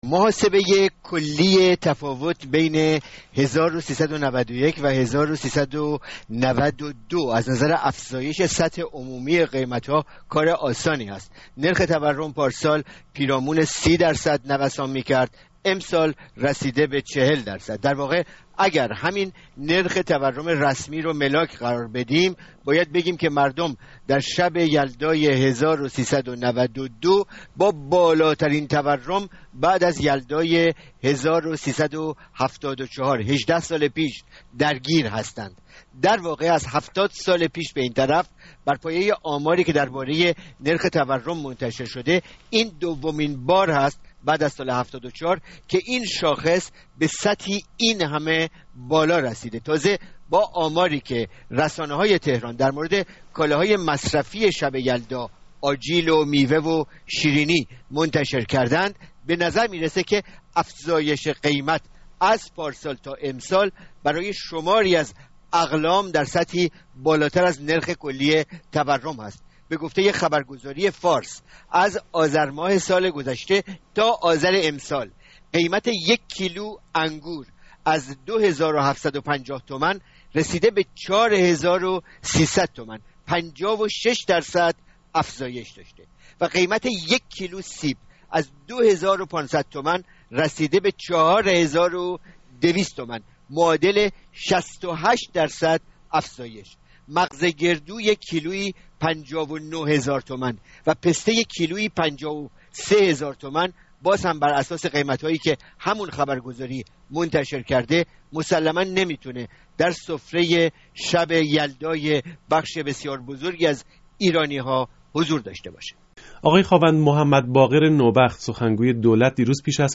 گفت‌وگوی